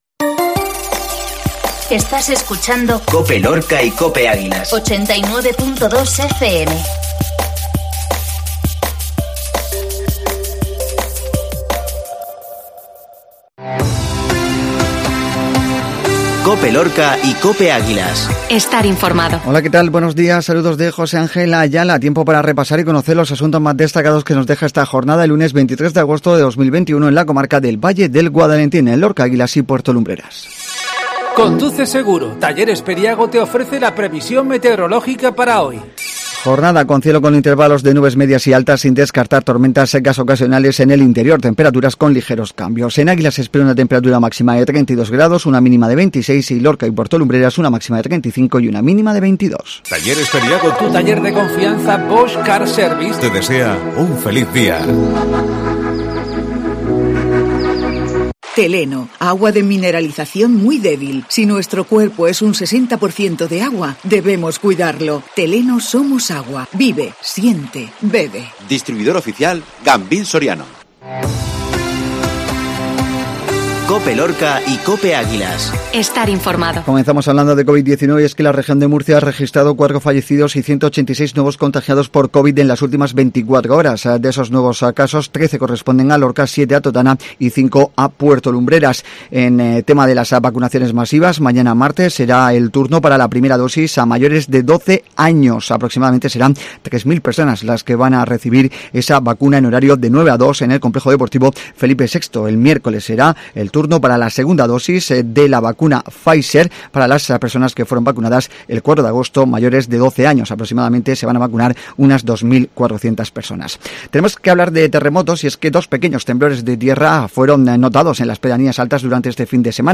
INFORMATIVO LUNES MATINAL